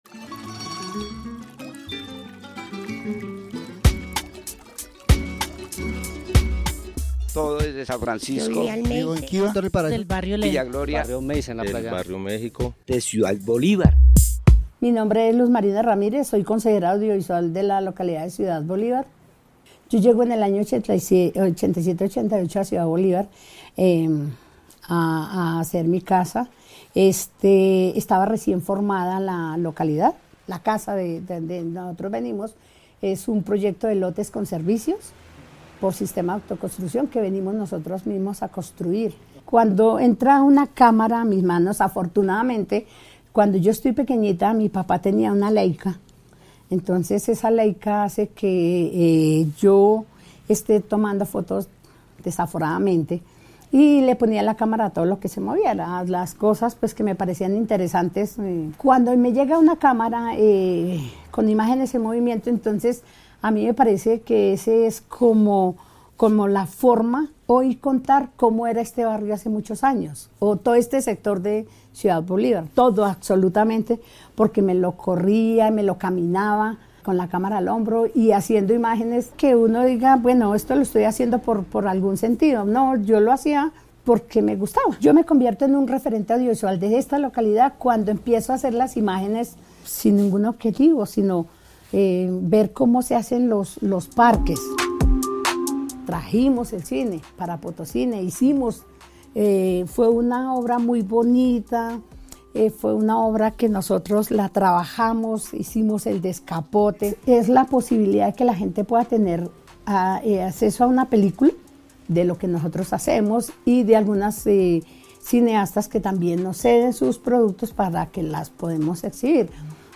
Testimonio de vida